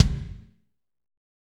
Index of /90_sSampleCDs/Northstar - Drumscapes Roland/DRM_Fast Rock/KIK_F_R Kicks x